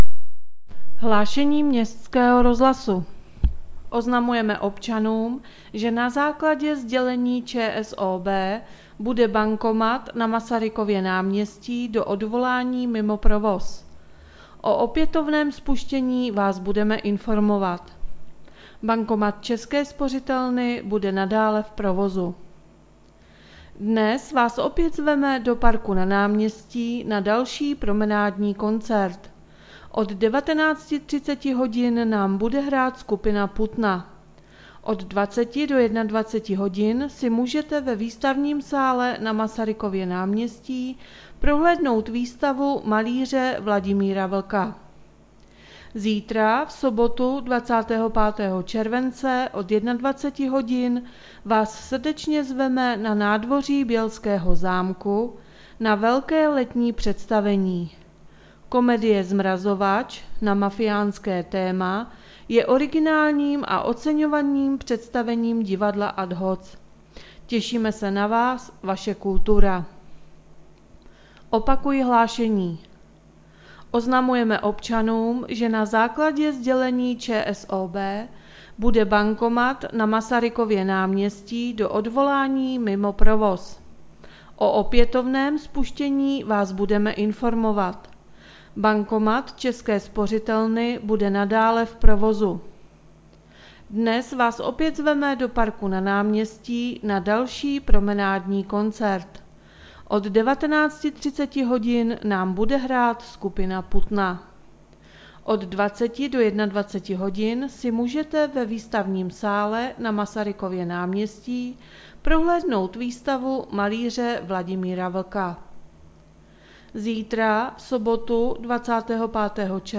Hlášení městského rozhlasu 24.7.2020